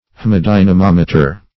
Haemadynamometer \H[ae]`ma*dy`na*mom"e*ter\ (h[=e]`m[.a]*d[imac]`n[.a]*m[o^]m"[-e]*t[~e]r or h[e^]m`[.a]*d[i^]n`[.a]-), Same as Hemadynamometer .